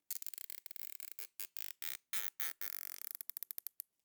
household
Zip Ties Secure 6